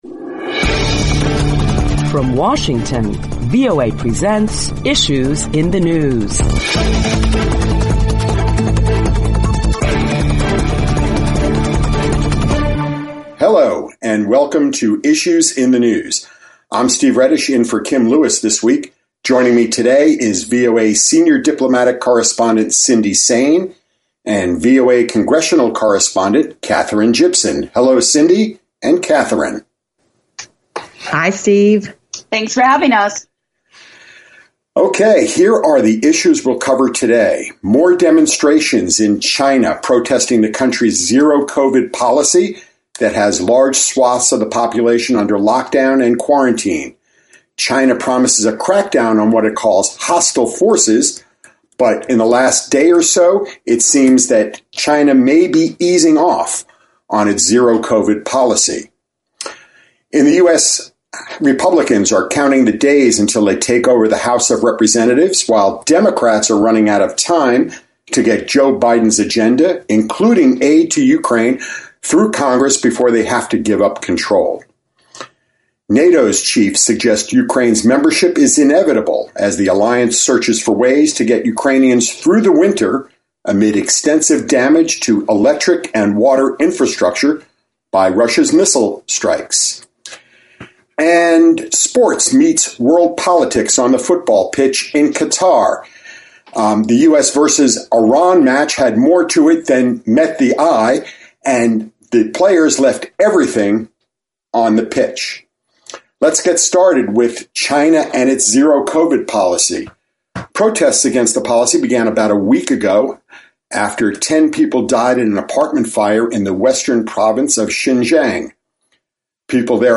Guest moderator